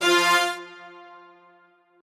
strings3_11.ogg